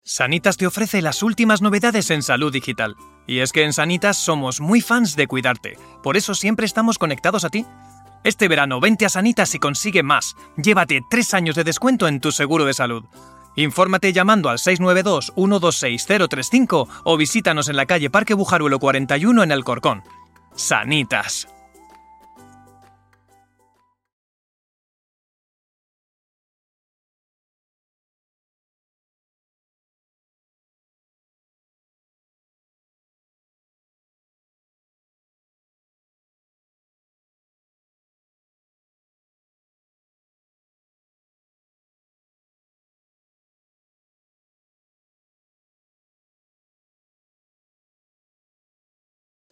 Así suenan algunas de nuestras voces profesionales para vídeos:
Vídeo Corporativo
demo-radio-cuna-radio-seguros-ondacero-2.mp3